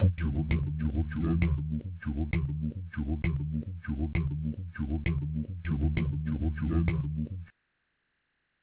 Il ya huit samples, qui constituent la banque de sons avec laquelle j'ai composé les quatres exemples de mixage disponibles sur cette page .
Pour vous montrer toute la mobilité que m'offre l'improvisation en concert avec des bandes crées en studio, j'ai mixé pour le Terrier quatre versions possibles de cette banque de samples.